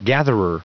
Prononciation du mot gatherer en anglais (fichier audio)
Prononciation du mot : gatherer